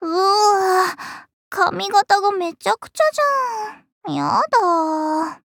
文件 文件历史 文件用途 全域文件用途 Ja_Fifi_amb_02.ogg （Ogg Vorbis声音文件，长度5.4秒，99 kbps，文件大小：66 KB） 源地址:游戏语音 文件历史 点击某个日期/时间查看对应时刻的文件。 日期/时间 缩略图 大小 用户 备注 当前 2018年5月25日 (五) 02:11 5.4秒 （66 KB） 地下城与勇士  （ 留言 | 贡献 ） 分类:祈求者比比 分类:地下城与勇士 源地址:游戏语音 您不可以覆盖此文件。